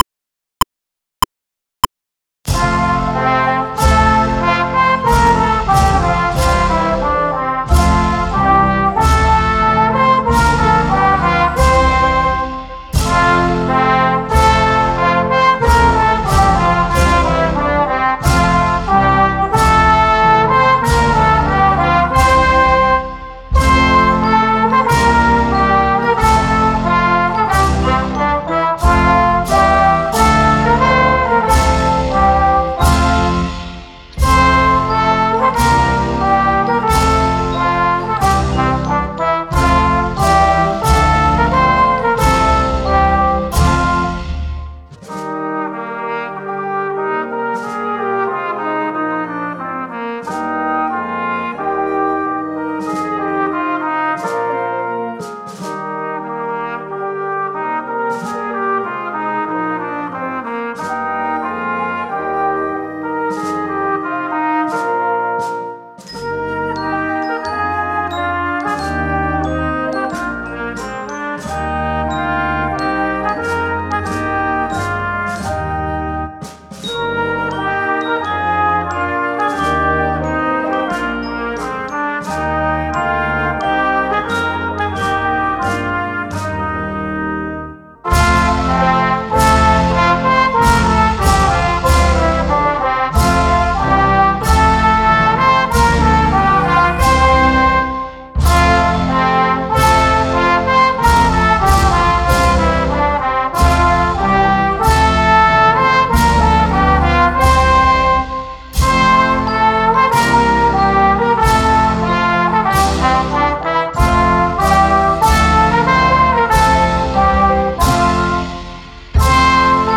sólo música